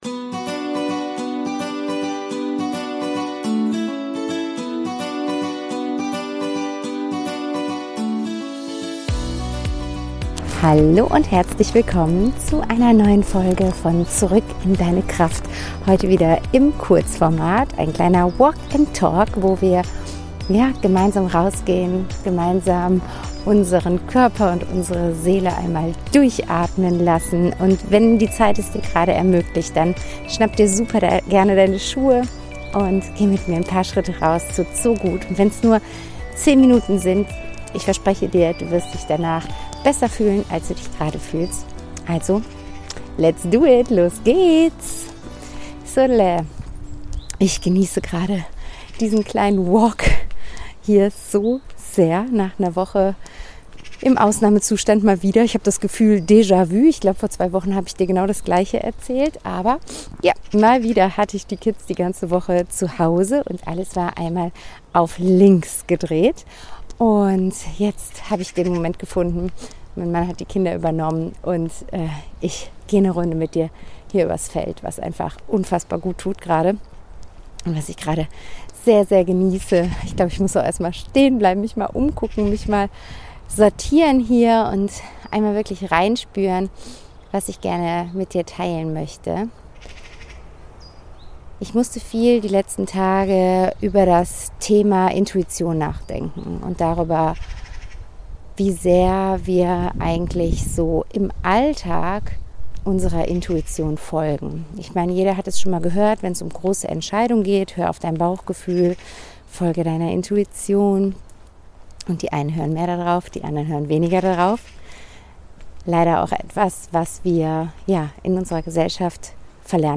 In diesen Folgen nehme ich dich mit auf einen Spaziergang. Beim Gehen teile ich spontane Gedanken, ehrliche Impulse und leise Fragen aus dem Moment heraus – unperfekt in der Tonqualität, dafür nah, authentisch und mitten aus dem Leben.